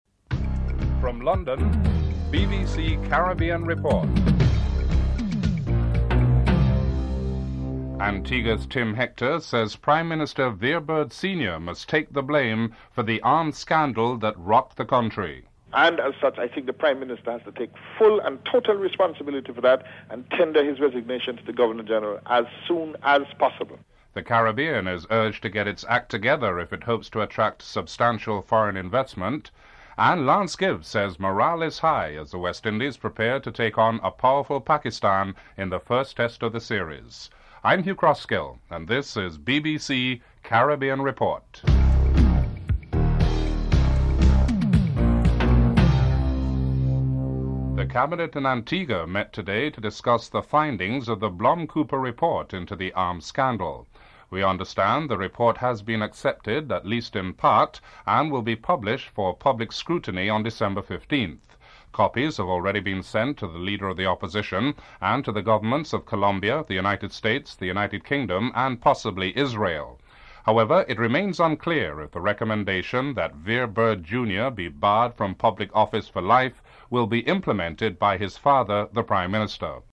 Distorted audio at the end of the report.